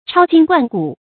超今冠古 注音： ㄔㄠ ㄐㄧㄣ ㄍㄨㄢ ㄍㄨˇ 讀音讀法： 意思解釋： 冠：超出眾人。